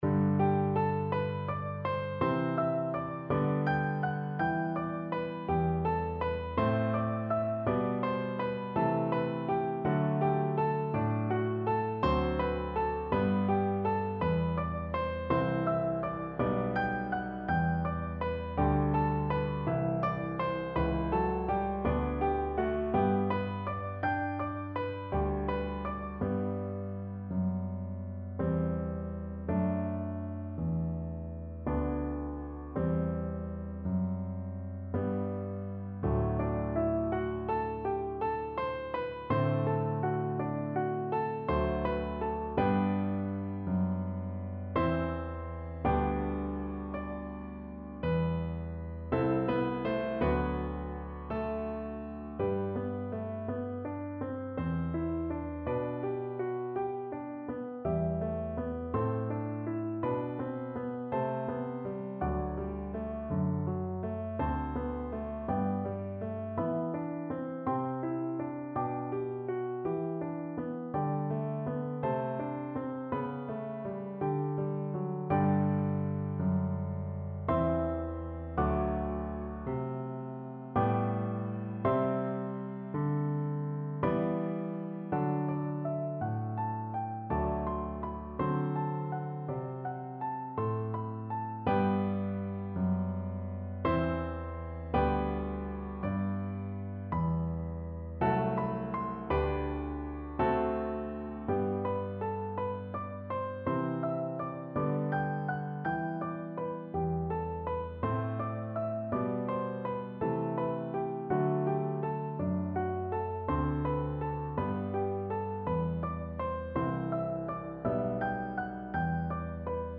piano duet, baroque